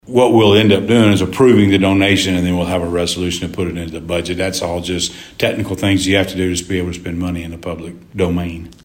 Mayor Maddox says the money will cover most of the cost for six sirens to be installed within the city limits.